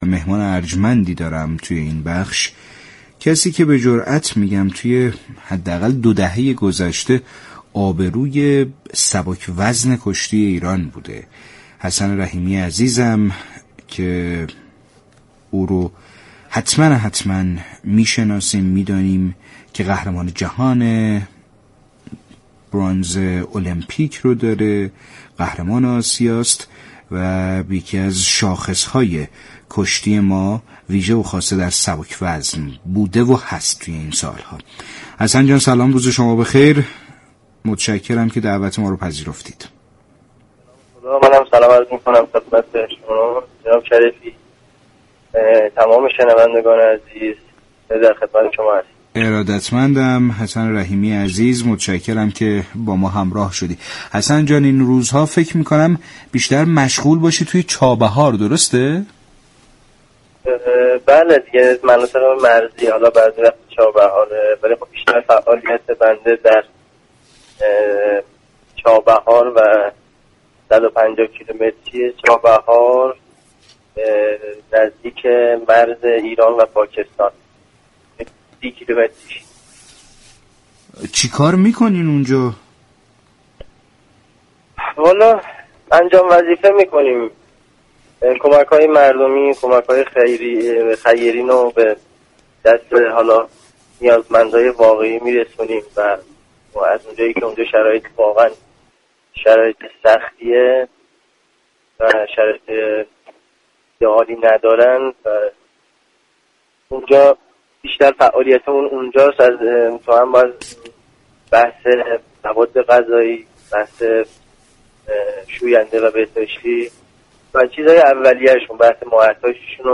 شما می توانید از طریق فایل صوتی پیوست شنونده این گفتگو با رادیو ورزش باشید.